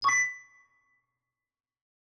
Scifi, Computer, Futuristic Technology, Confirm Tones, Tonal SND58787 S02 - Pitch 134_0.wav